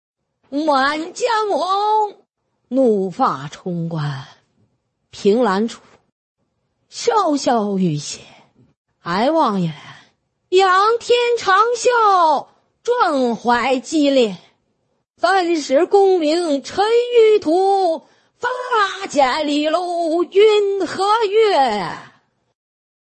而声音转换（Voice Conversion）是根据发声者的说话音频，将音频中的音色转换到目标发音人上，并保持说话内容、韵律情感等一致。将发声者的情感、抑扬顿挫、停顿等一并保留并输出。
声音转换-中文.mp3